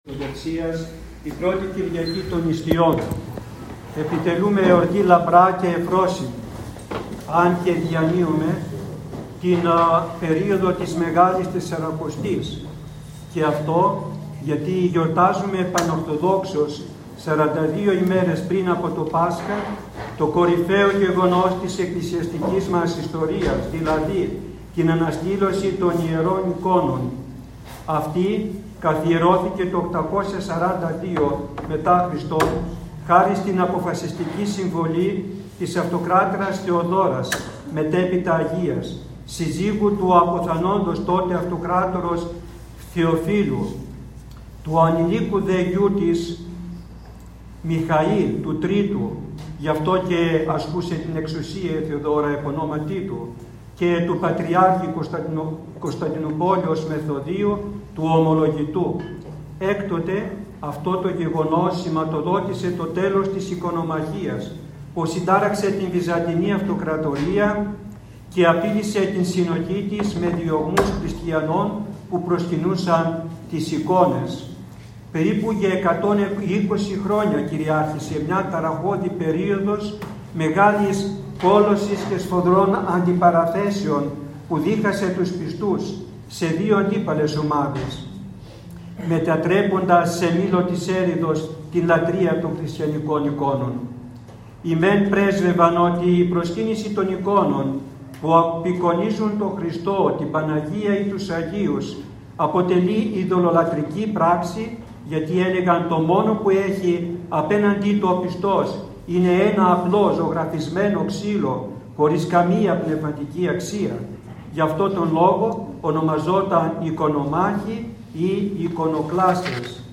Ὁμιλία